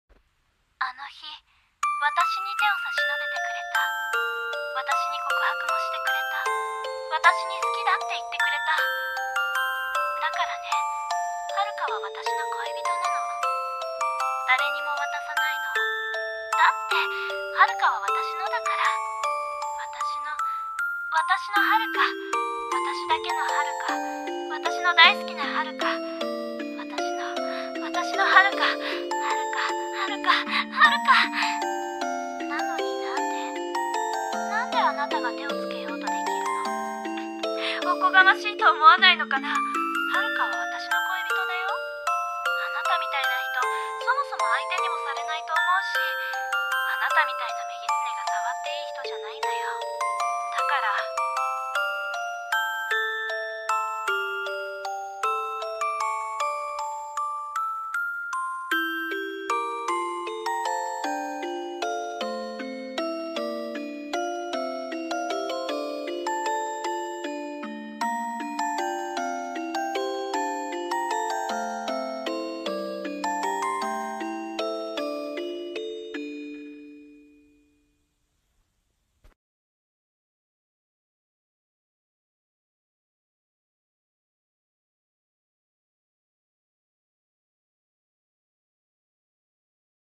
ヤンデレ台本